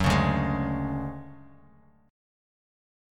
DM#11 chord